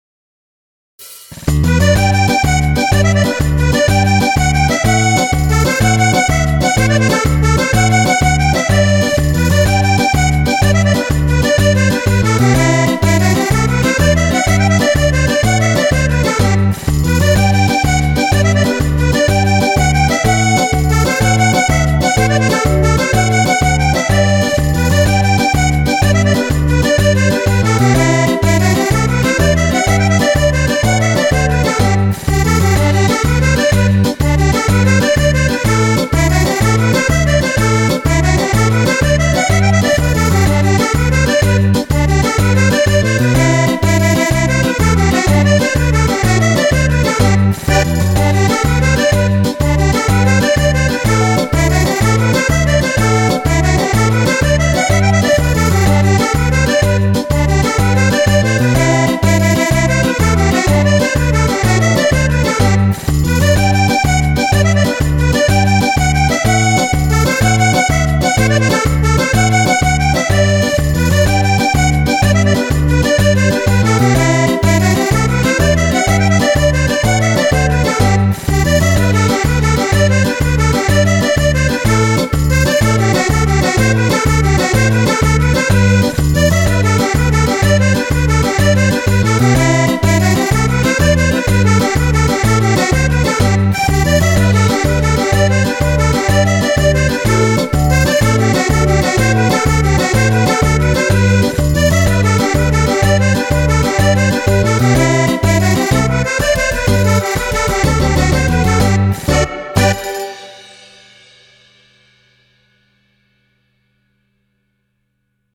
5 ballabili per organetto
Tarantella